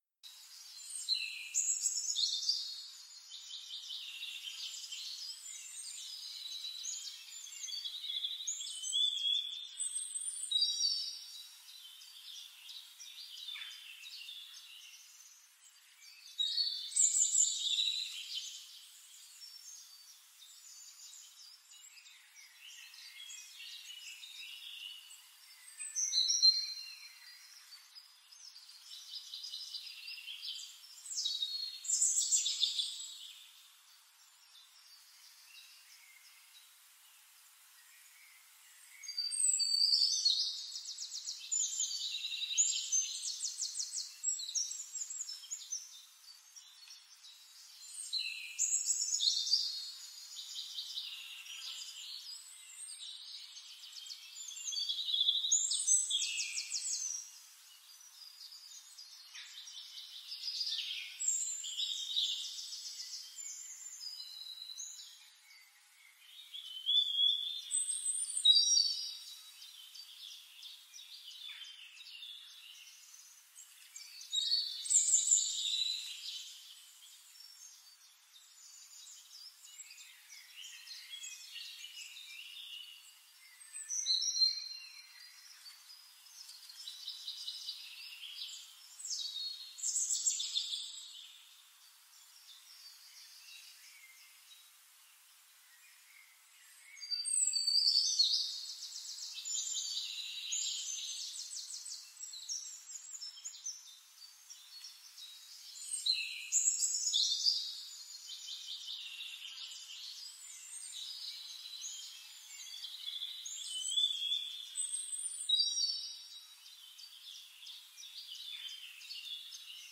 دانلود صدای طبیعت و پرندگان